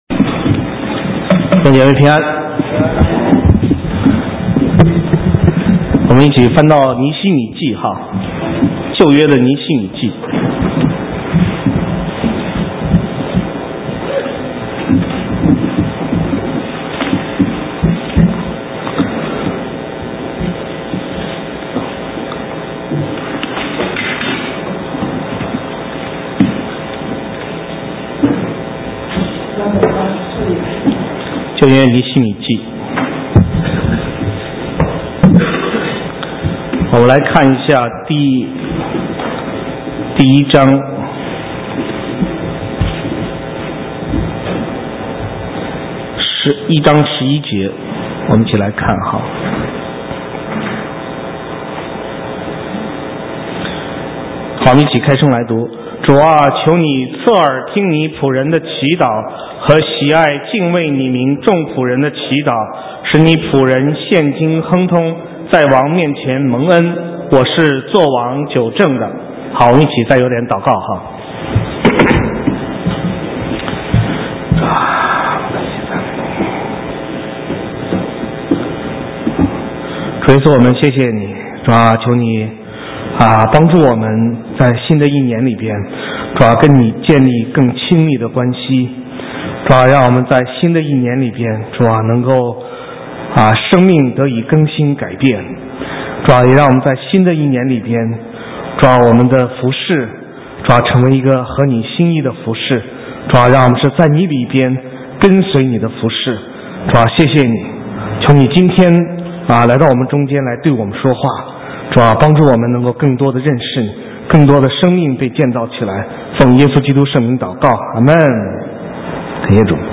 神州宣教--讲道录音 浏览：向尼希米学习 (2011-01-02)